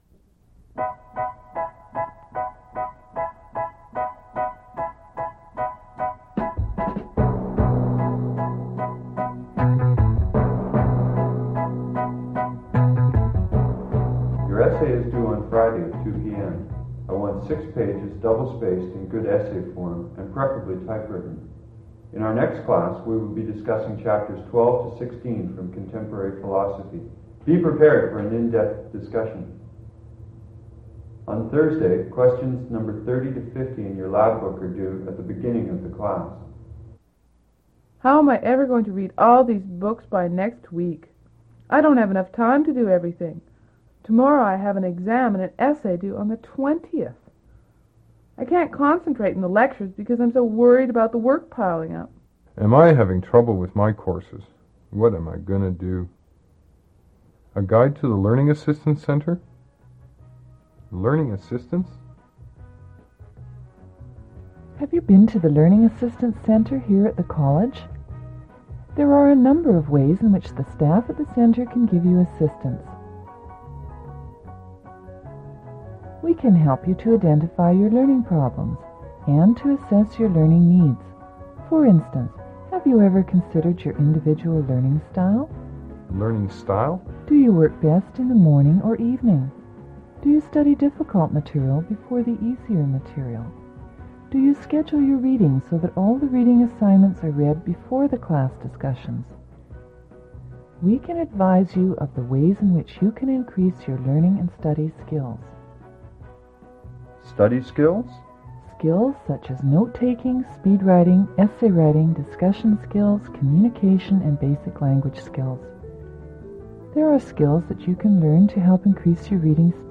Audio non-musical
Voiceover narrative with background music, describing the services and benefits of the Learning Assistance Centre.
audio cassette